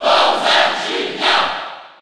Category:Crowd cheers (SSB4) You cannot overwrite this file.
Bowser_Jr._Cheer_French_PAL_SSB4.ogg